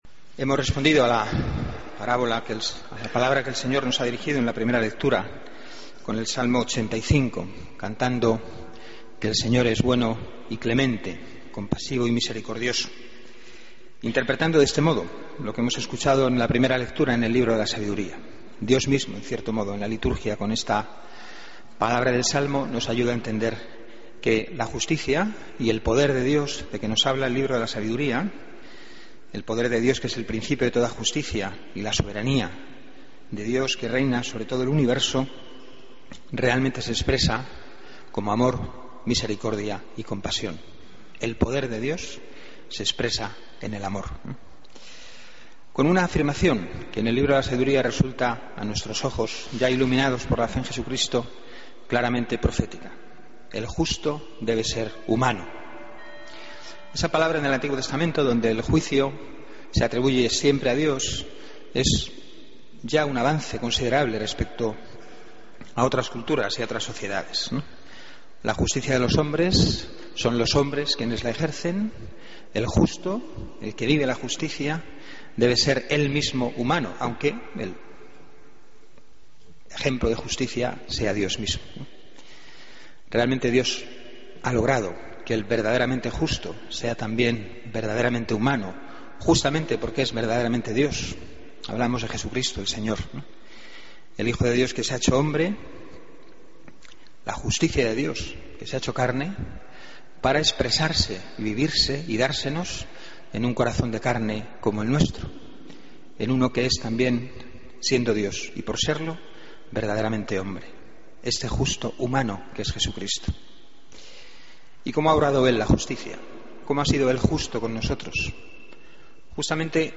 Homilía del Domingo 20 de Julio de 2014